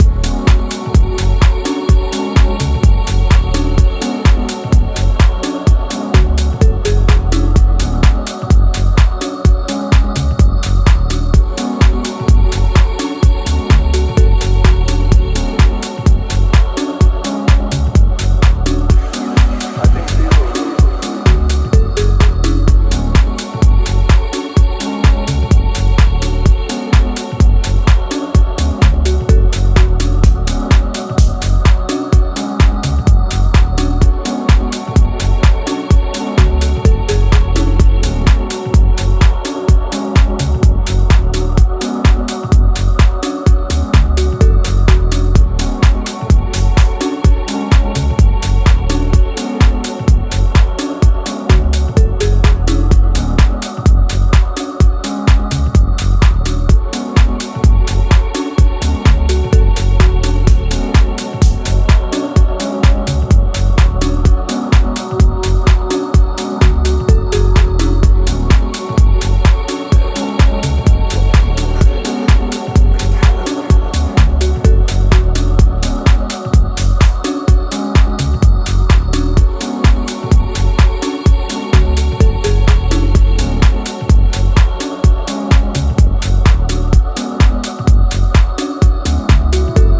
ジャンル(スタイル) DEEP HOUSE / HOUSE / TECH HOUSE